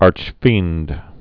(ärch-fēnd)